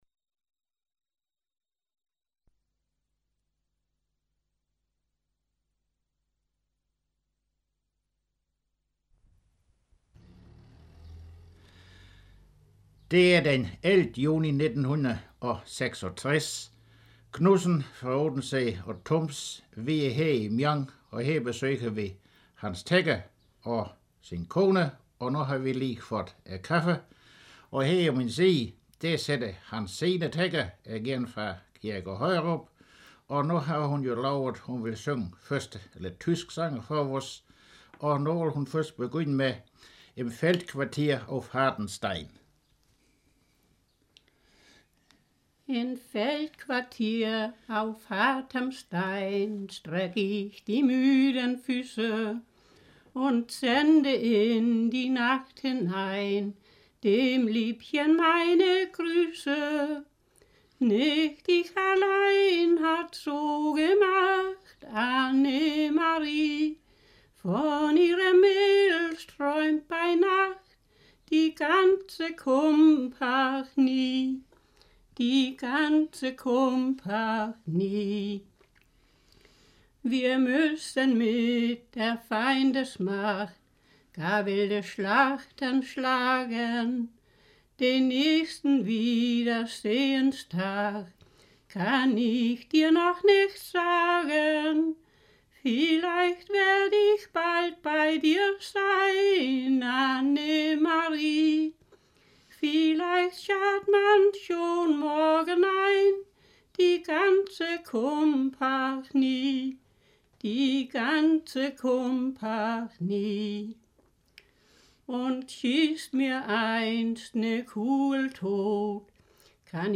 Bånd 248 · Sønderjyder synger